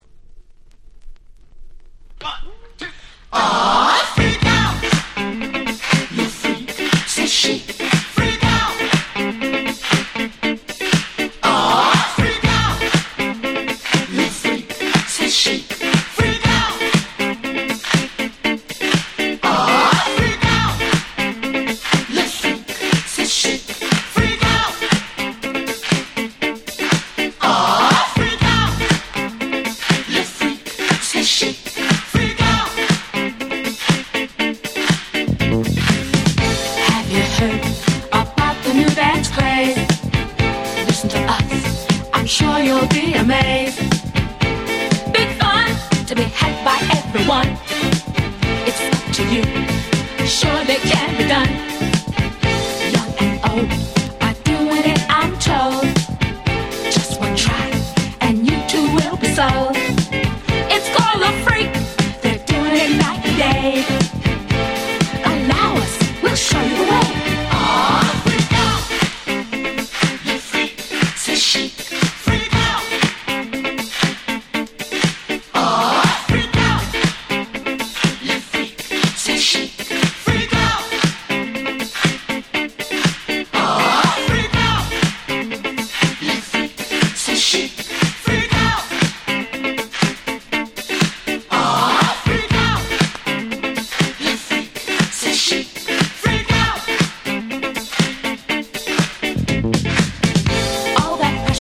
【Media】Vinyl 12'' Single
シックディスコダンクラ Boogie ブギーダンスクラシック Soul ソウル